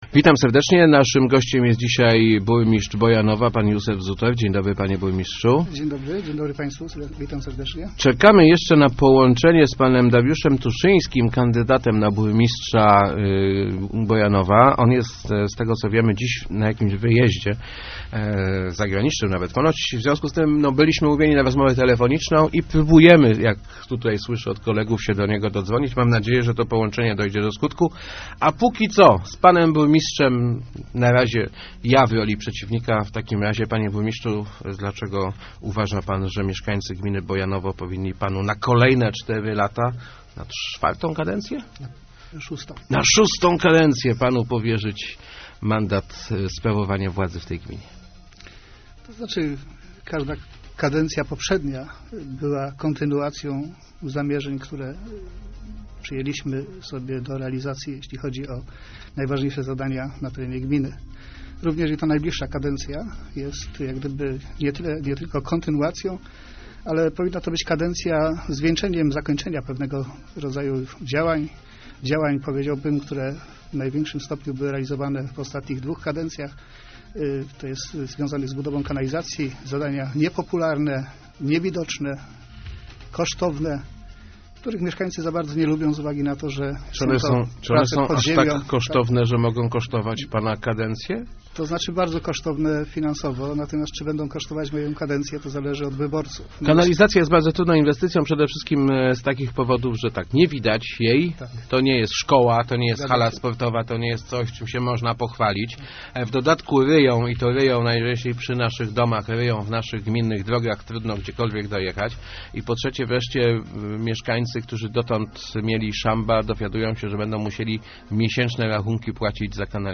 Dokończenie budowy sieci kanalizacyjnej w całej gminie i budowa przedszkola w Bojanowie to najważniejsze zamierzenia ubiegającego się o reelekcję burmistrza Józefa Zutera. Kontynuujemy zamierzenia z minionych lat - mówił w Rozmowach Elki.